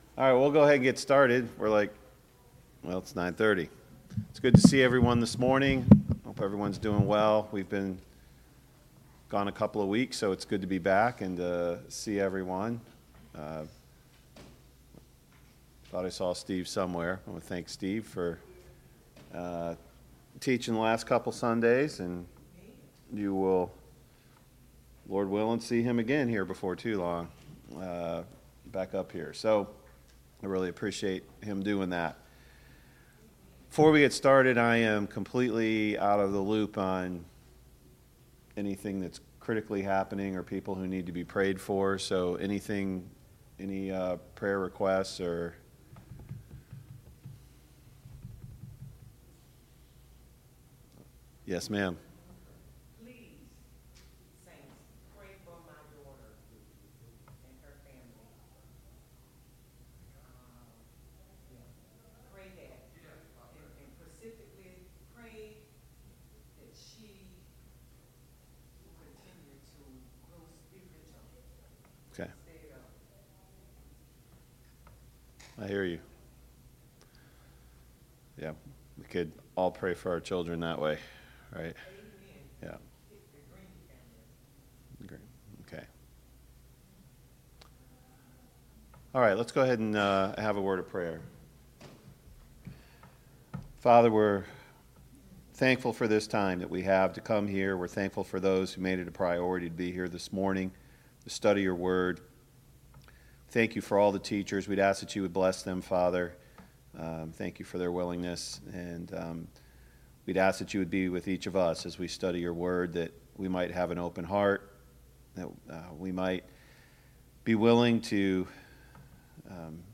A Study of the Christian Attitude Service Type: Sunday Morning Bible Class « 3.